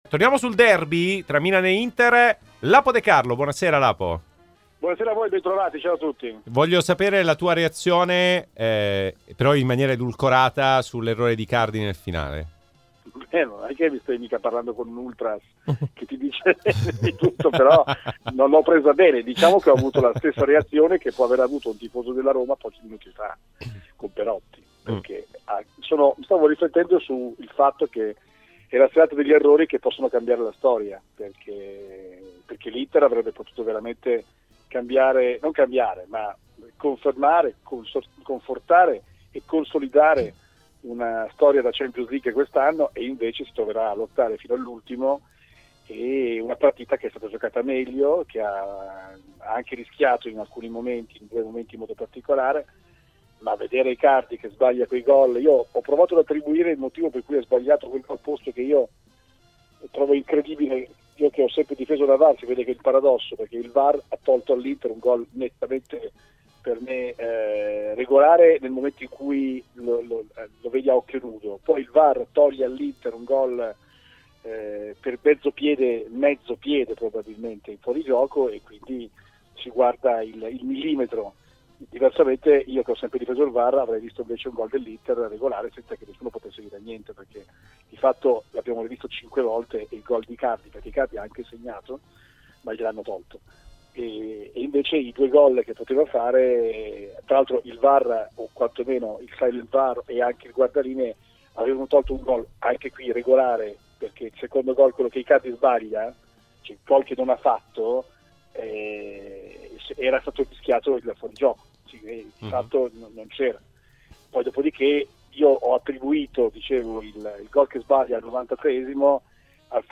a commento di Milan-Inter 0-0.